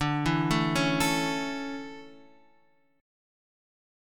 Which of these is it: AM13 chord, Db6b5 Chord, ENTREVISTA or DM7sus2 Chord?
DM7sus2 Chord